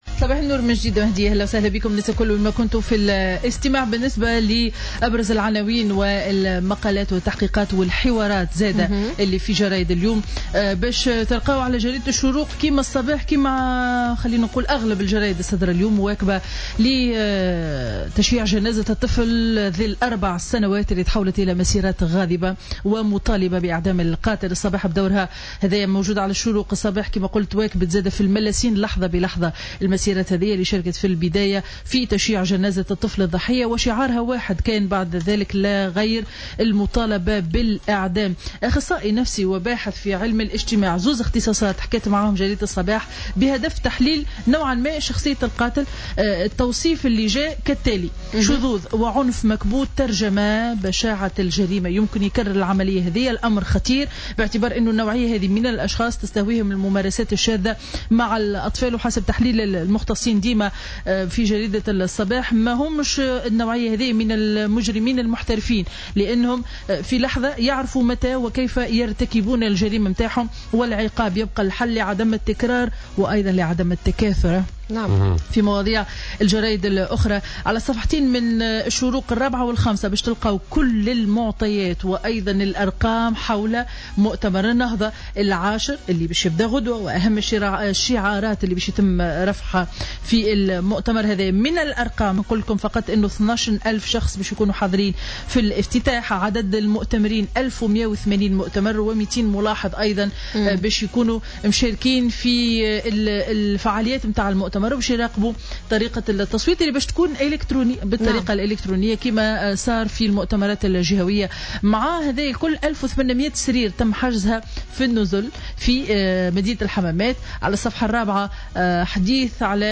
Revue de presse du jeudi 19 mai 2016